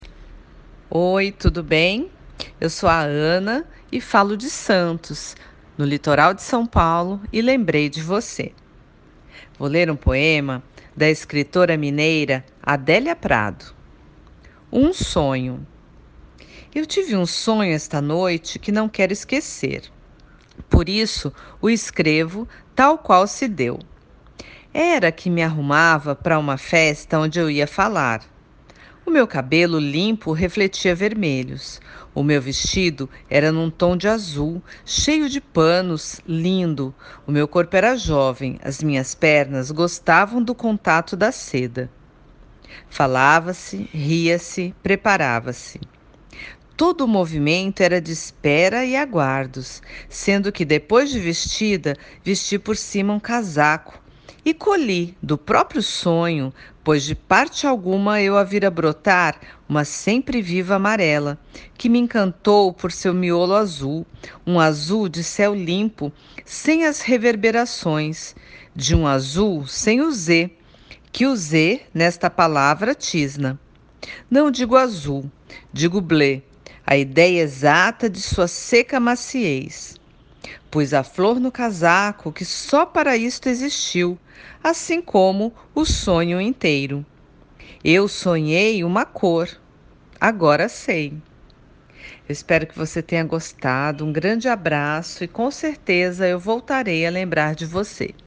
Poema Português